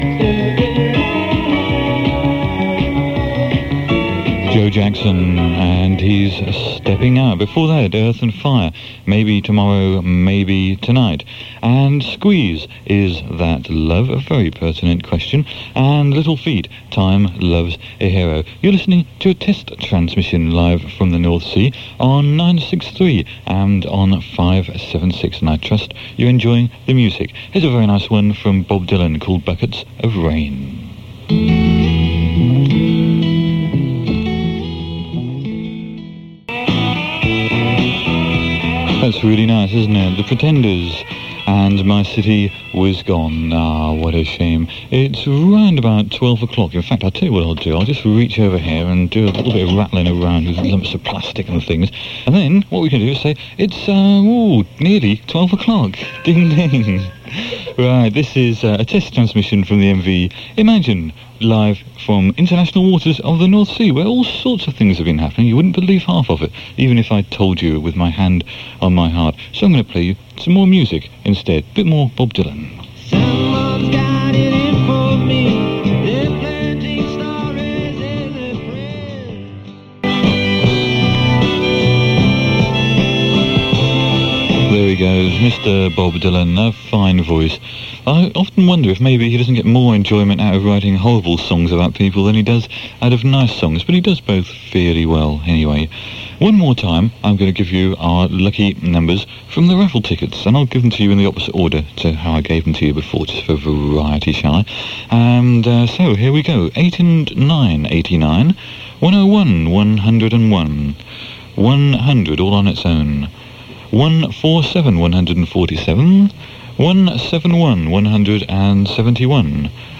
with a “test transmission from the mv Imagine” on 8th January 1985